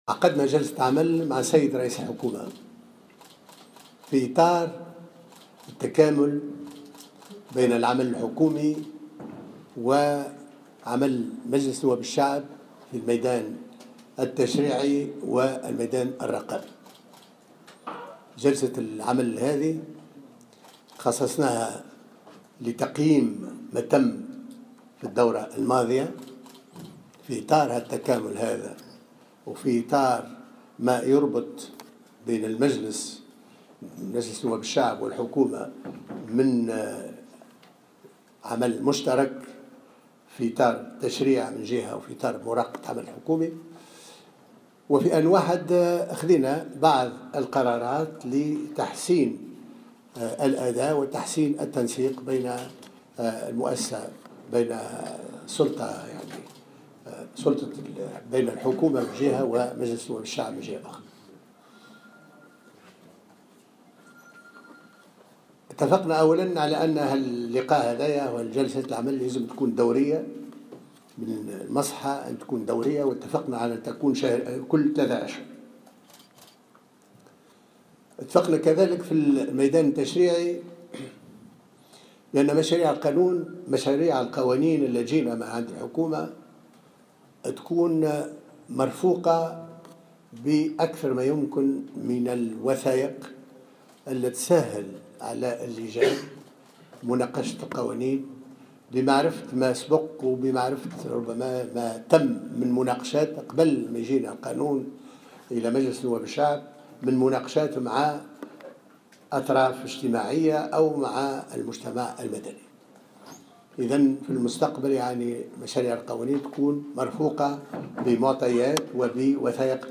قال رئيس مجلس نواب الشعب محمد الناصر في تصريح لمراسل الجوهرة اف ام، إنه تم خلال اللقاء الذي جمعه برئيس الحكومة يوسف الشاهد، تقييم العمل المشترك خلال الدورة بين المجلس والحكومة واتخاذ عدة قرارات.